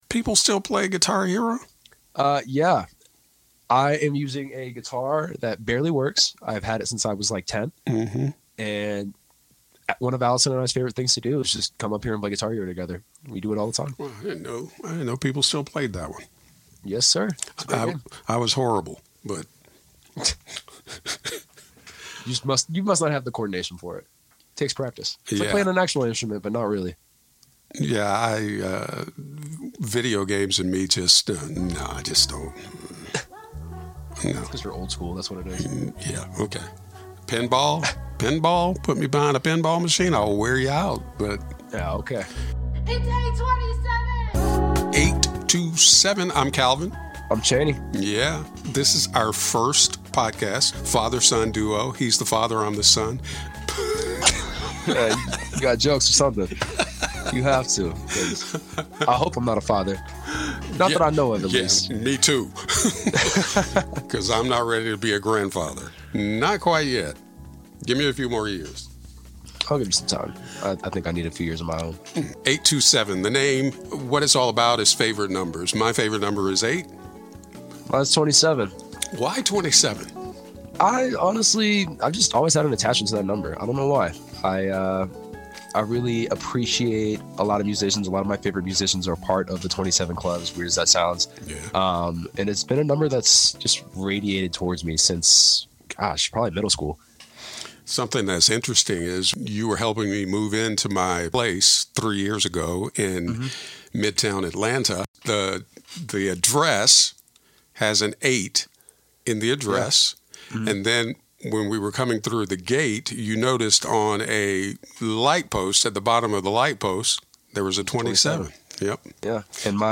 Two Generations. One Conversation.